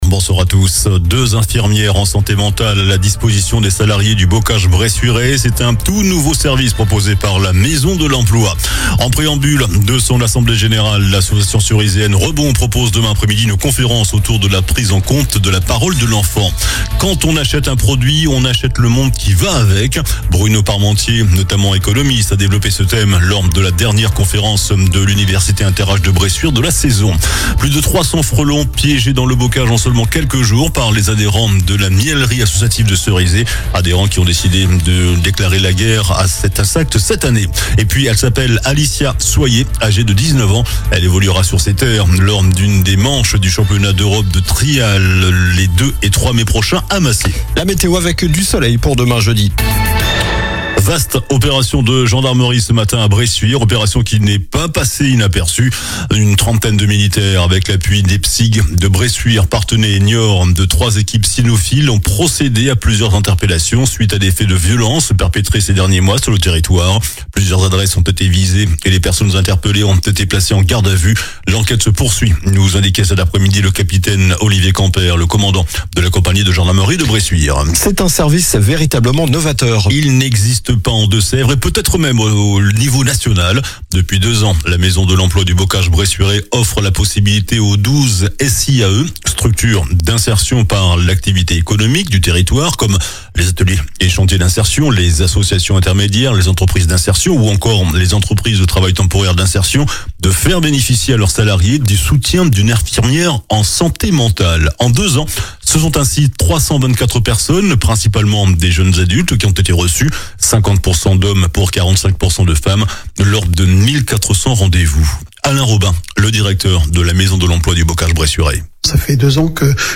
JOURNAL DU MERCREDI 22 AVRIL ( SOIR )